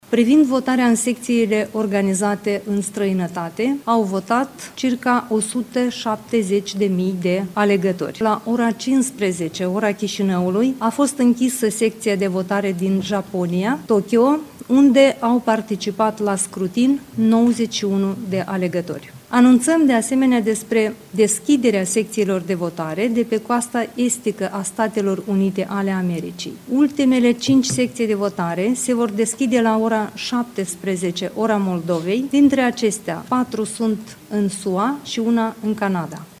Șefa Comisiei Electorală Centrală de la Chișinău, Angela Caraman: „Privind votarea în secțiile organizate în străinătate, au votat 170 de mii de alegători”